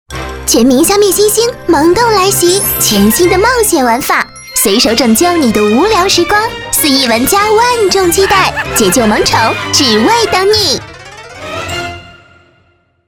女国90_广告_品牌形象_游戏_可爱.mp3